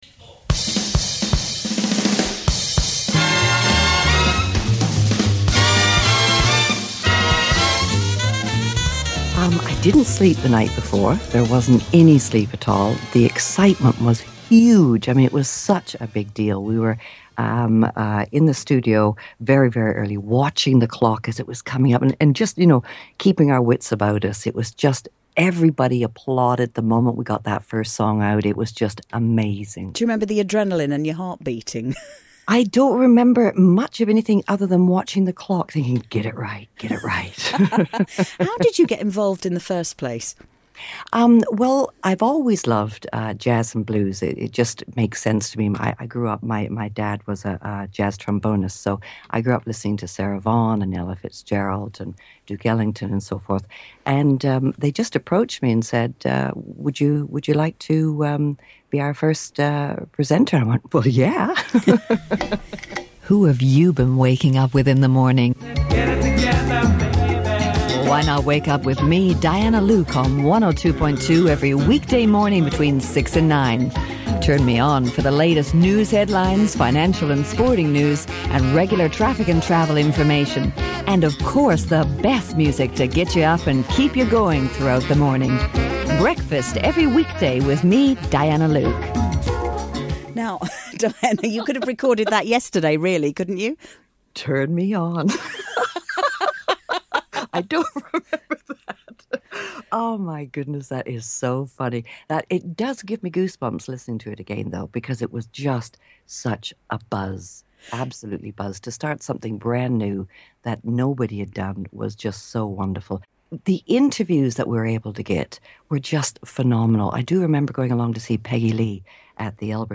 Jazz FM (London) launch commentary - 1990